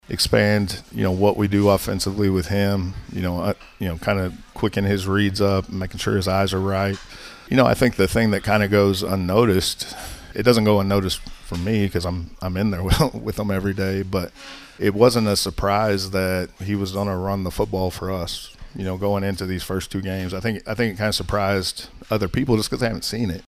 during Wednesday night’s weekly radio show from Dink’s Pit BBQ.